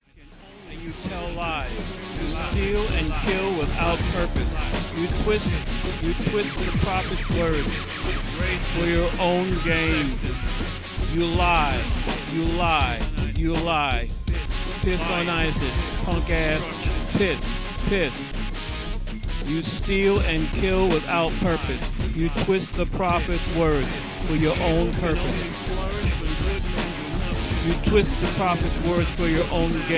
a rock metal anthem gothic pro american anthem